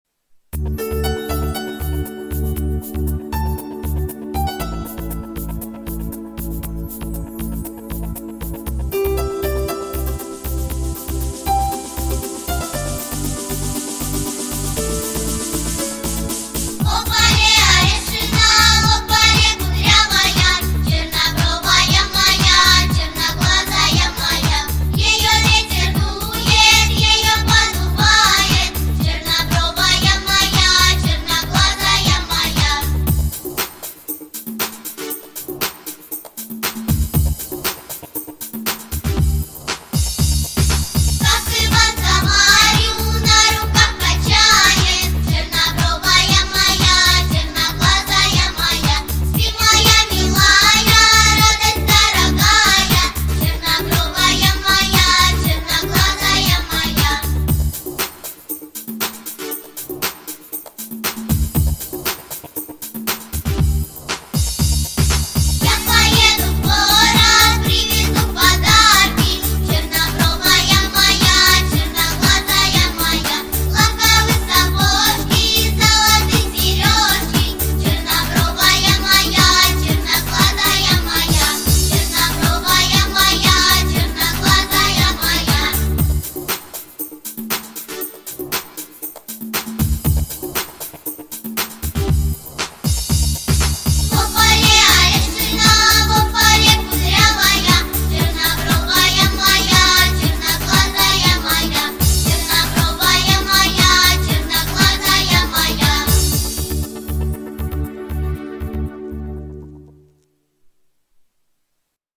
• Жанр: Детские песни